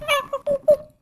Monkey Imitation 1
Category 🐾 Animals
animal animals ape apes chimp chimpanzee chimpanzees chimps sound effect free sound royalty free Animals